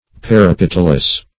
Definition of parapetalous.
parapetalous.mp3